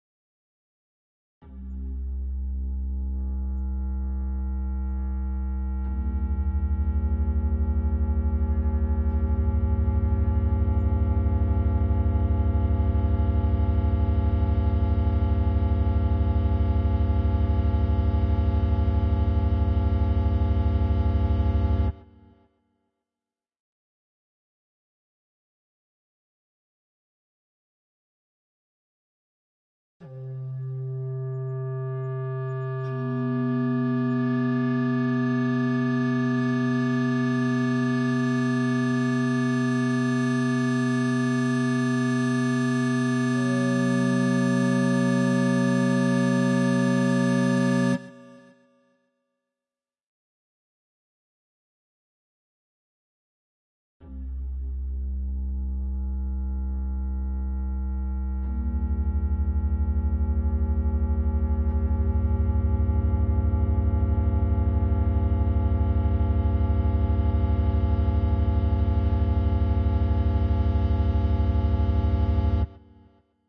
空间站的氛围
描述：空间站/船的游戏级别的氛围
标签： 阴森恐怖 令人毛骨悚然 闹鬼 吓人
声道立体声